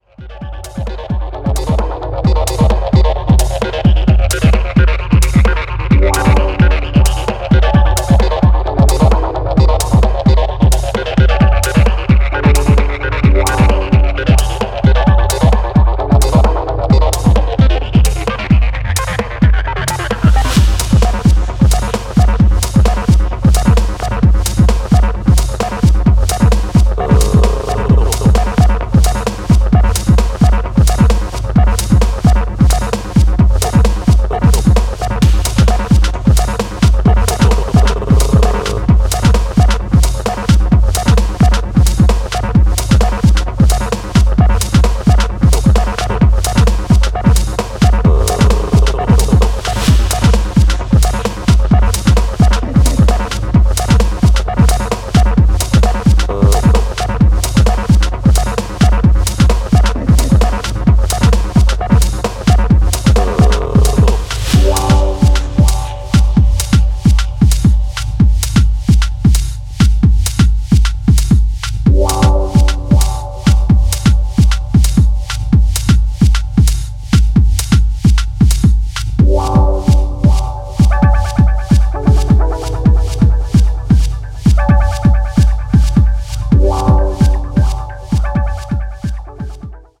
robust, hard-banging sound palette
single kick drum